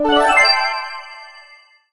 get_pickup_06.ogg